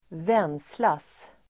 Ladda ner uttalet
Uttal: [²v'en:slas]